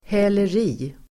Ladda ner uttalet
Uttal: [hä:ler'i:]